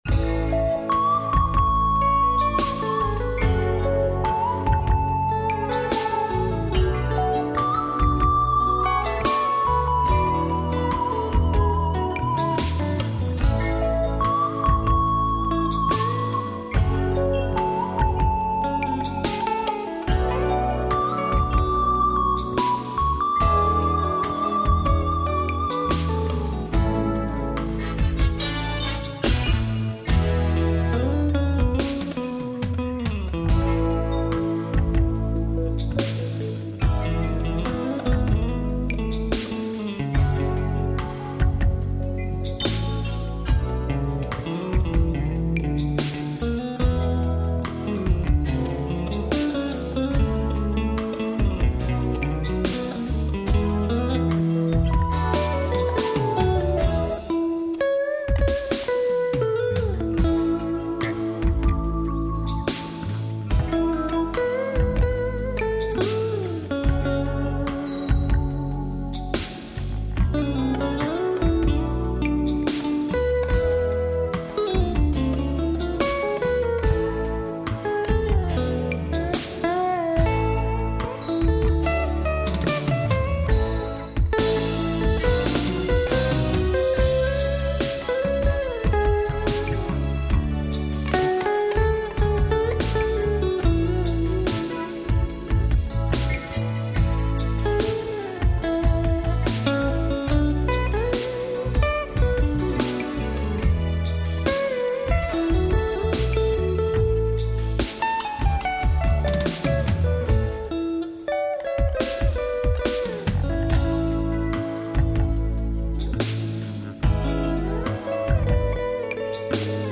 * Thể loại: Việt Nam